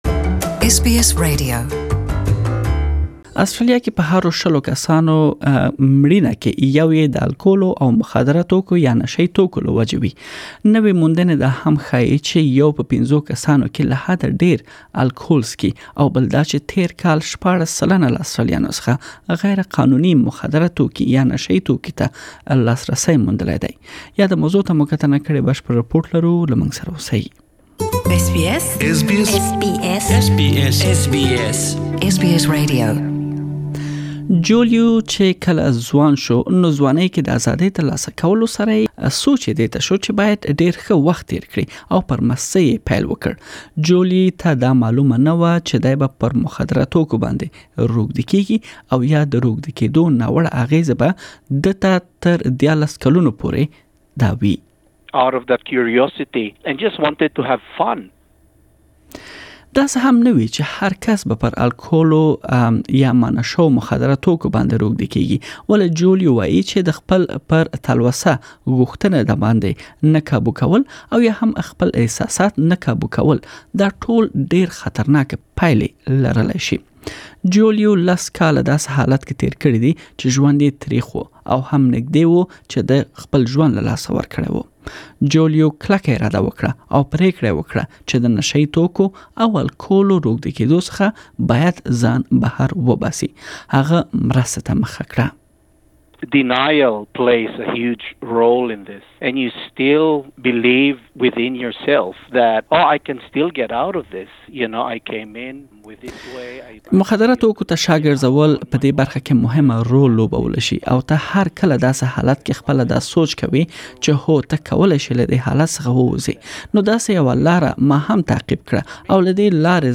Please listen to the full report in Pashto language.